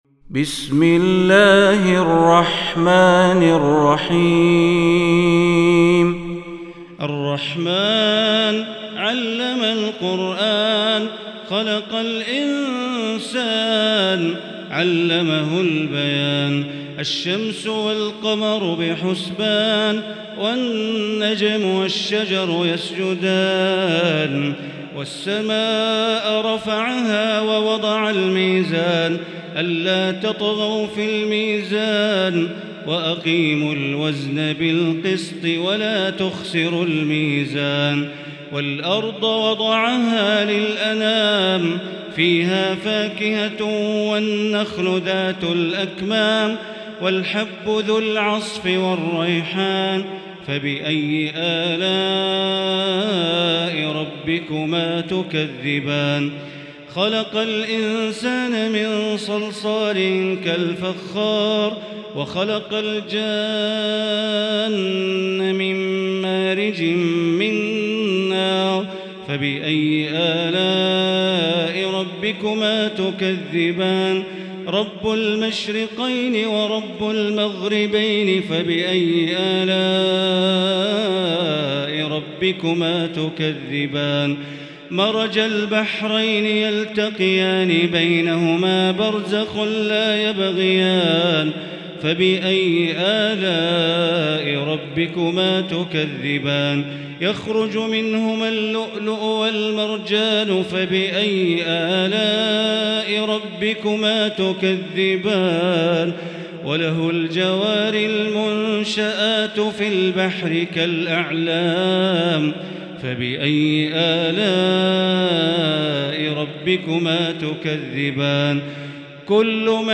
المكان: المسجد الحرام الشيخ: معالي الشيخ أ.د. بندر بليلة معالي الشيخ أ.د. بندر بليلة الرحمن